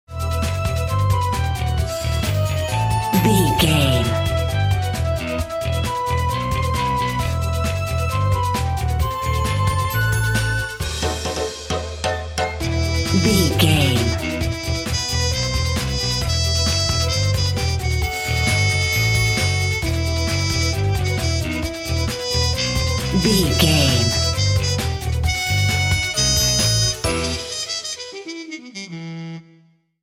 Aeolian/Minor
Fast
aggressive
driving
dark
dramatic
groovy
frantic
suspense
horns
violin
bass guitar
drums
trumpet
brass
70s